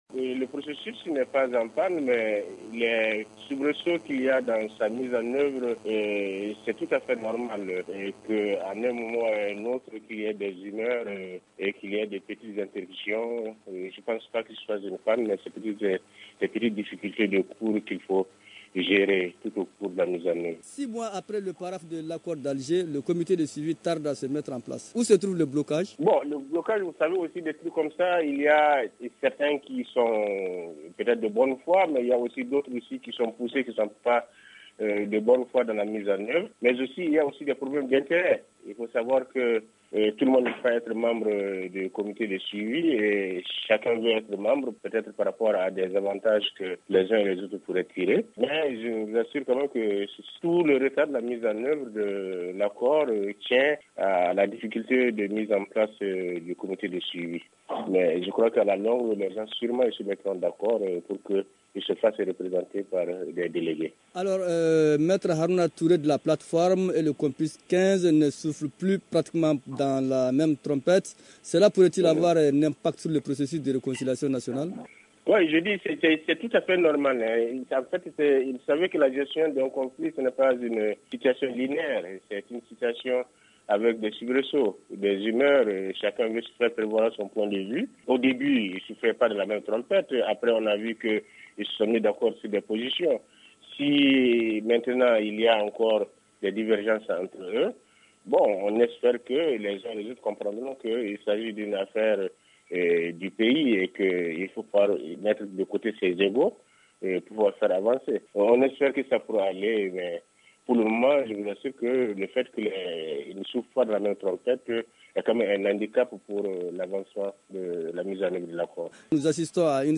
Joint au téléphone